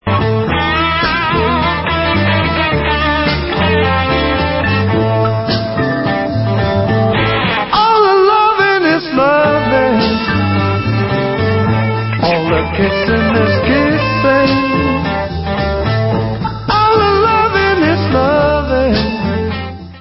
Stereo Version